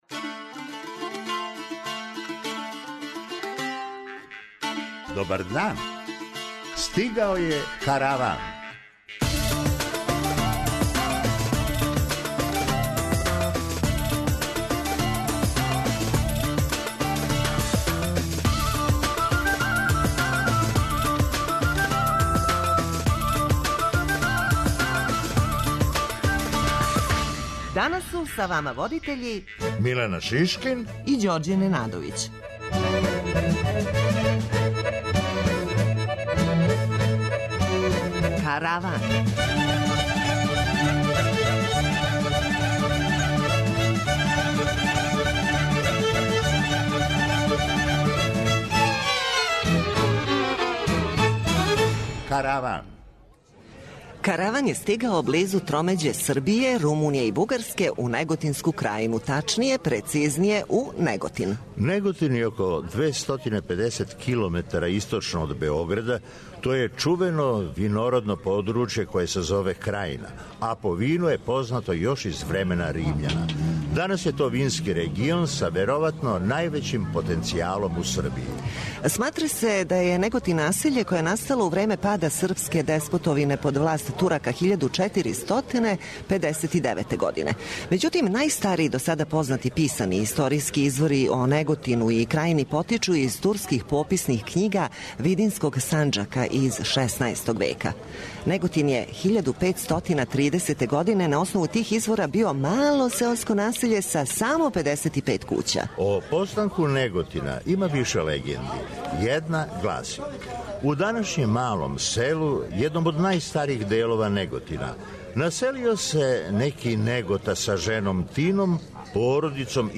Караван је на тромеђи Србије, Румуније и Бугарске, у Неготинској крајини, тачније у Неготину.